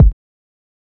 Rack Kick3.wav